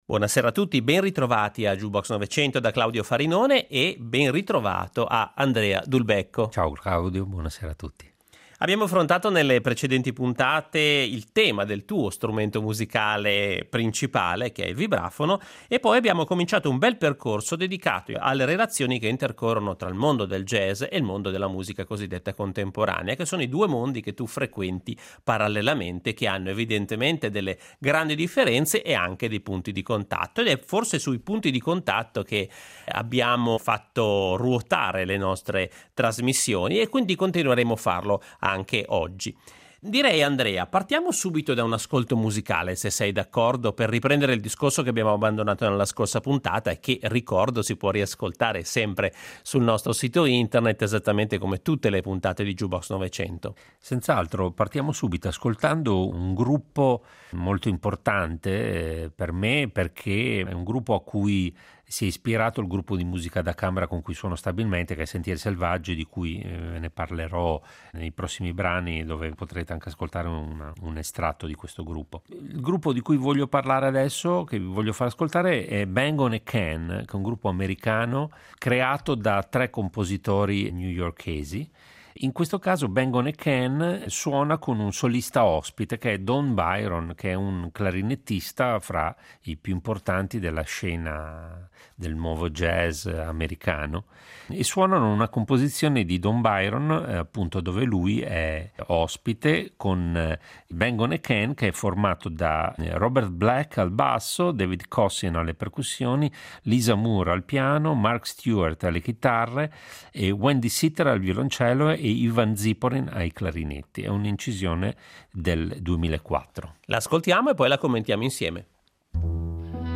Avrete modo di ascoltare i primi esperimenti di utilizzo di materiale jazzistico in composizioni di musica colta avvenute intorno alla metà del secolo scorso, fino ad arrivare ai giorni nostri. Inoltre, ascolterete gli esperimenti di improvvisatori che si cimentano con forme affini al repertorio classico contemporaneo.